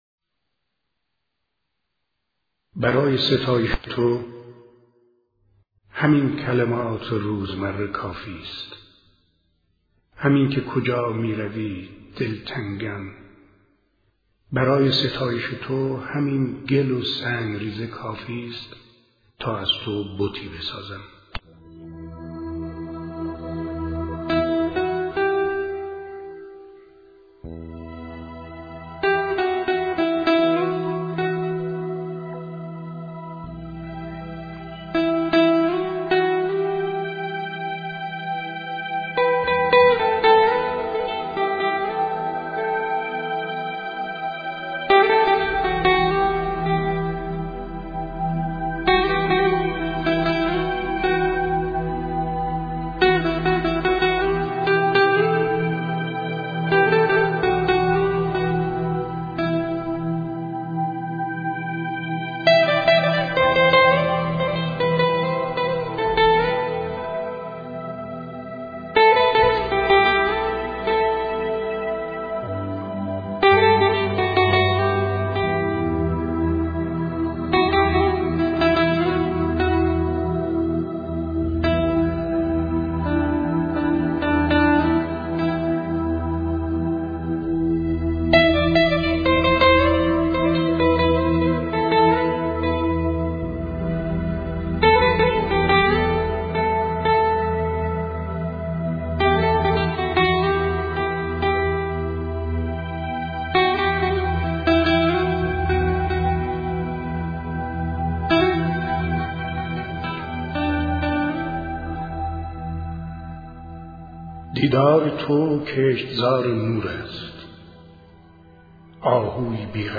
صدای شاعر – شمس لنگرودی